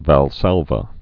(văl-sălvə, väl-sälvä)